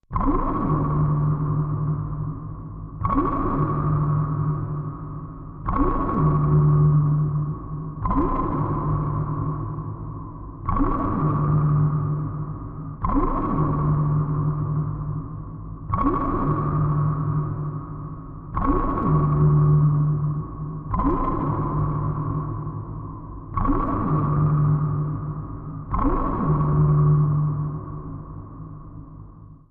Sub Shop, Machine, Sonar, Underwater, Pulsing, Static, Deep Fluid